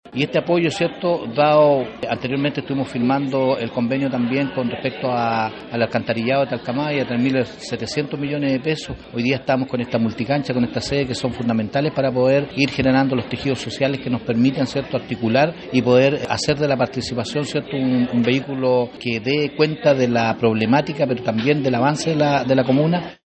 El alcalde de la comuna, Ricardo Fuentes, entregó mayores detalles sobre los proyectos a concretar, los que contribuirán al progreso de los sectores de Placilla, Unihue y Las Galaxias.